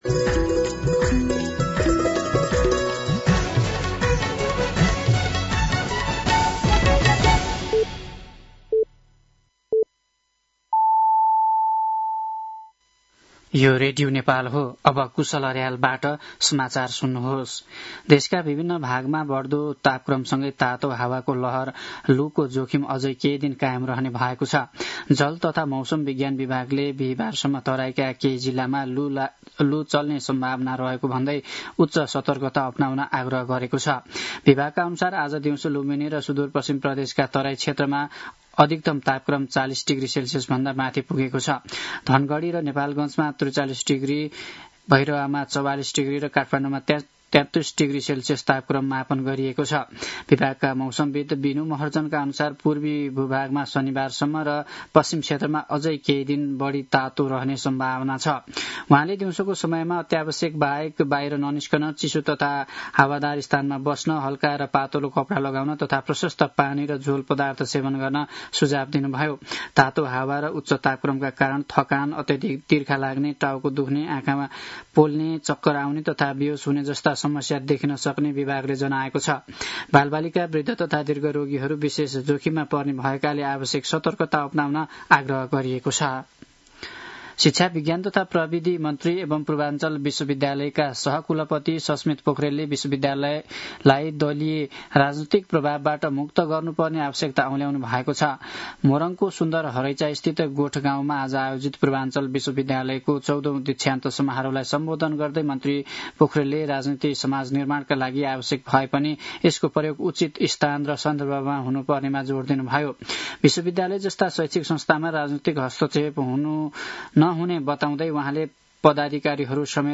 साँझ ५ बजेको नेपाली समाचार : ८ वैशाख , २०८३